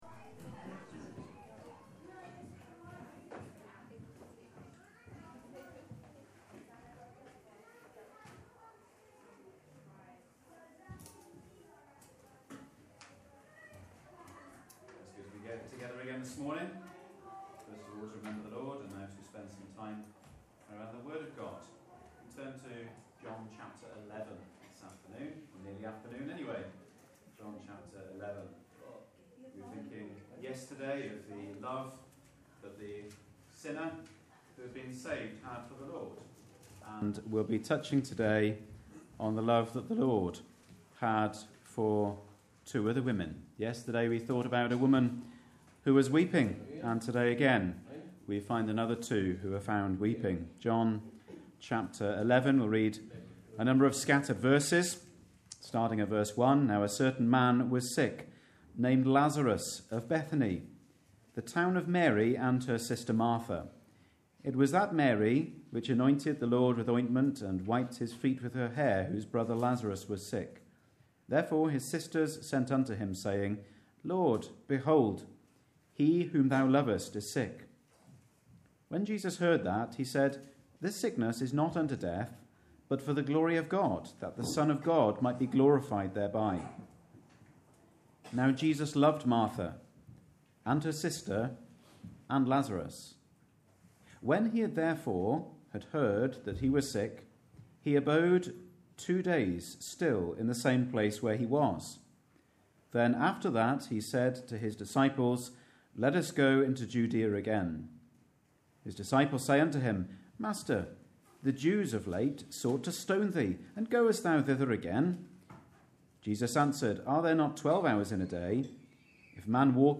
A recording of our Regular Saturday Night Ministry Meeting.